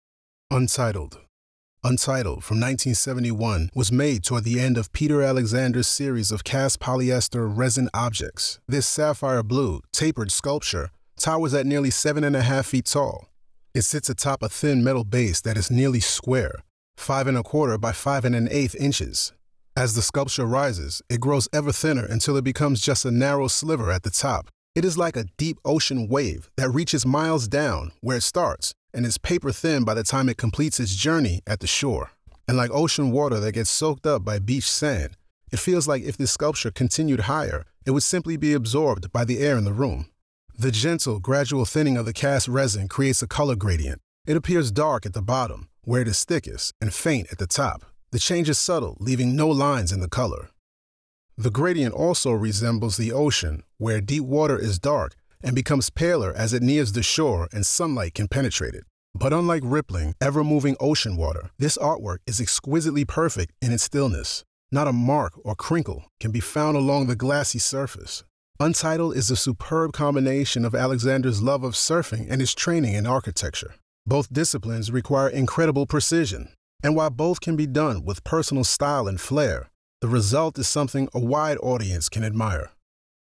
Audio Description (01:23)